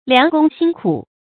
良工心苦 注音： ㄌㄧㄤˊ ㄍㄨㄙ ㄒㄧㄣ ㄎㄨˇ 讀音讀法： 意思解釋： 良工：手藝高明的工匠。